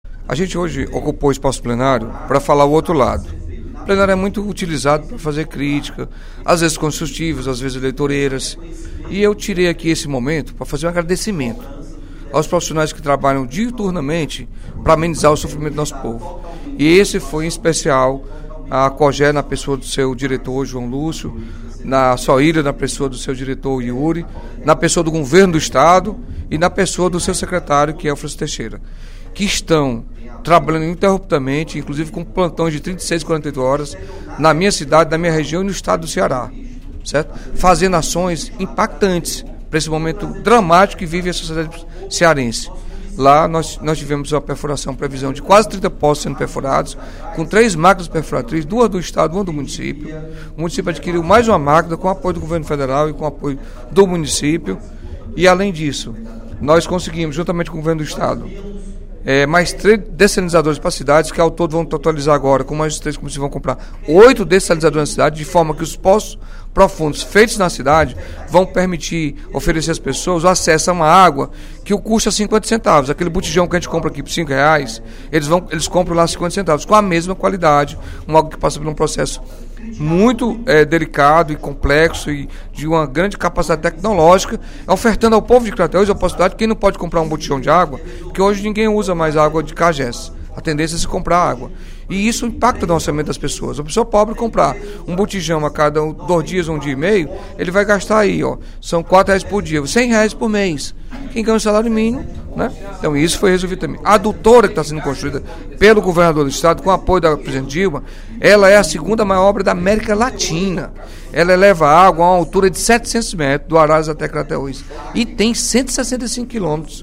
O deputado Carlos Felipe (PCdoB) destacou, durante o primeiro expediente da sessão plenária desta quarta-feira (25/02), as obras hídricas que estão sendo realizadas pelo Governo do Estado e pela Prefeitura de Crateús para ofertar água à população daquele município.